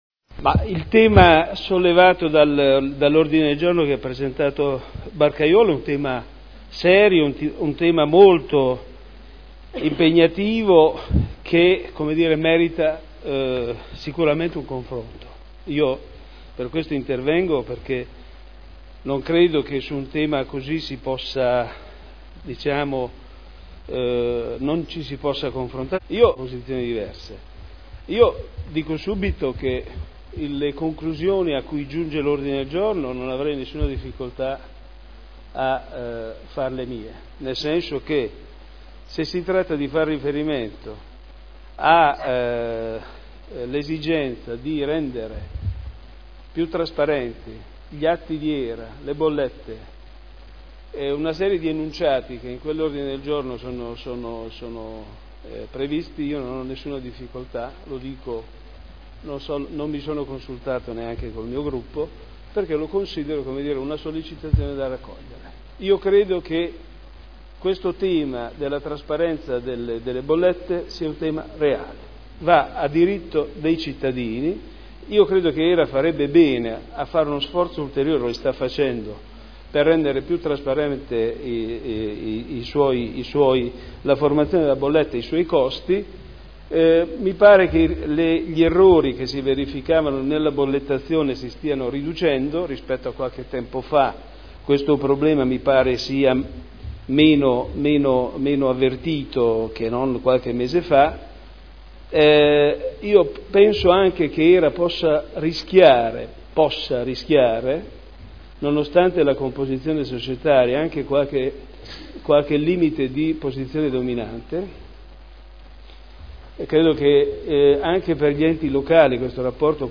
Mozione presentata dai consiglieri Barcaiuolo, Taddei, Galli, Pellacani, Morandi, Bellei, Vecchi, Santoro (PdL) avente per oggetto: "Trasparenza HERA" Dibattito